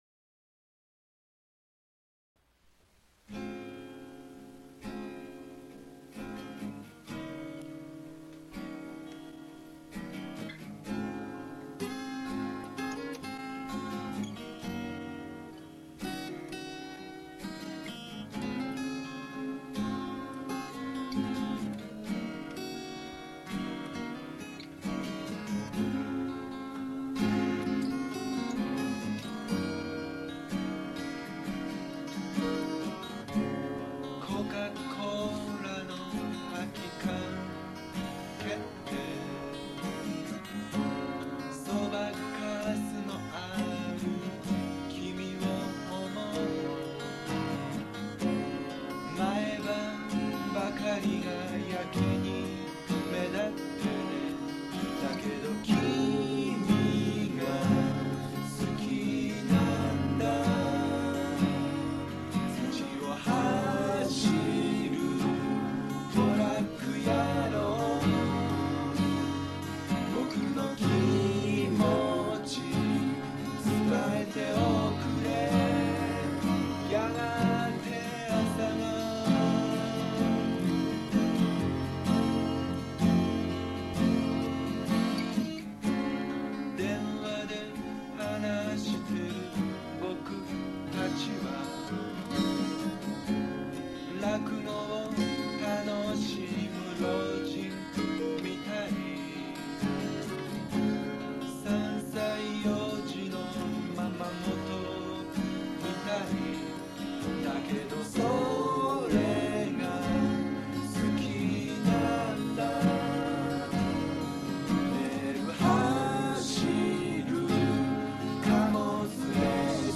音やリズムは外れ いろんなところで間違っているけど　何かとっても新鮮。